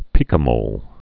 (pēkə-mōl, pī-)